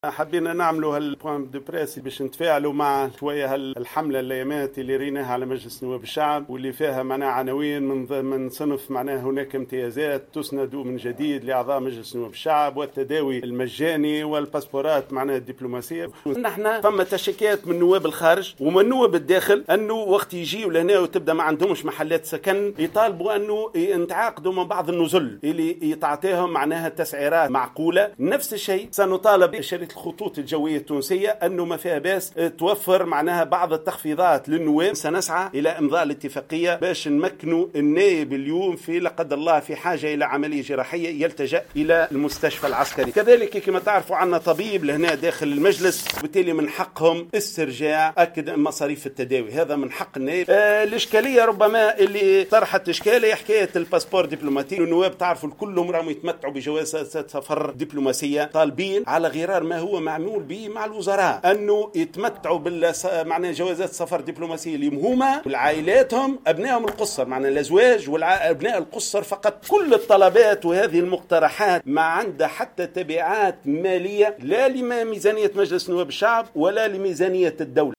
قال غازي الشواشي مساعد رئيس مجلس نواب الشعب المكلف بشؤون النواب خلال ندوة صحفية صباح اليوم بالبرلمان لتوضيح ما راج حول "امتيازات جديدة للنواب"، إن الامتيازات التي يطالب بها النواب ضرورة لتسهيل عملهم وتحسين مردودهم.